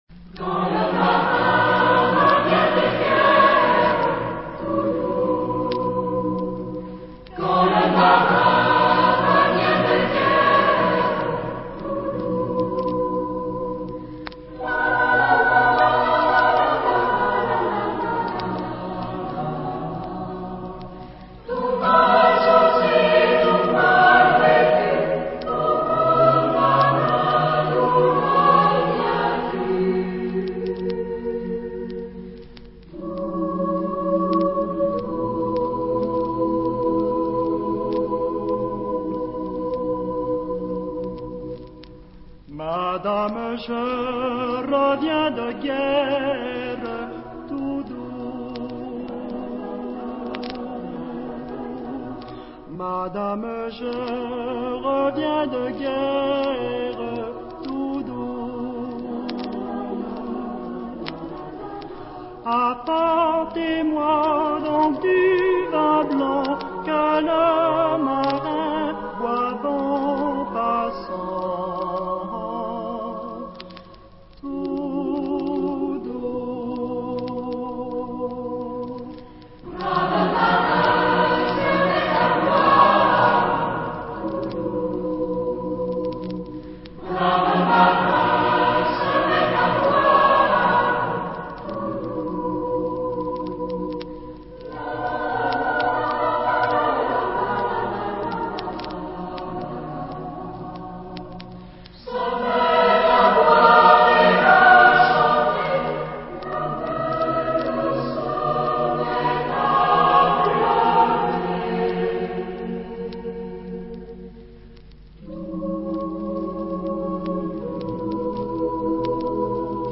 SATB (4 voix mixtes).
Chanson à boire. Populaire.
Consultable sous : Populaire Francophone Acappella Consultable sous : JS-Populaire Francophone A Cappella